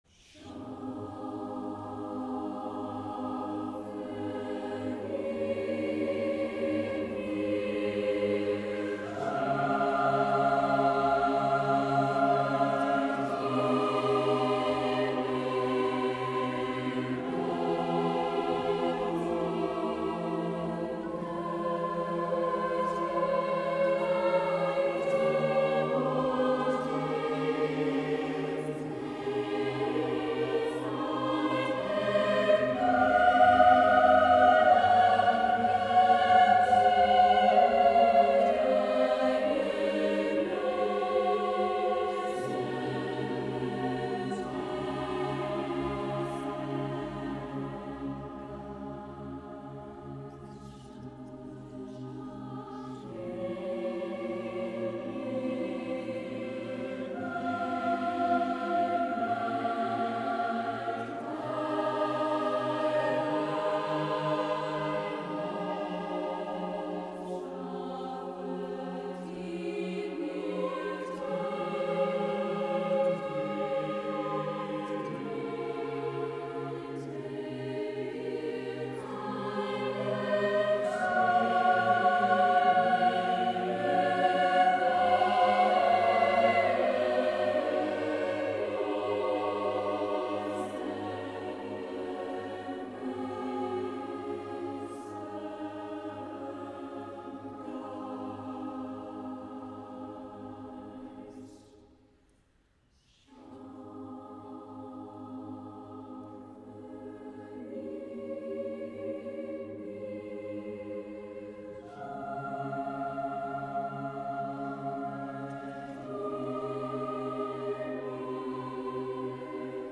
Prima’s first appearance in St. Andrews Cathedral                       Click on each thumbnail below to see full programme)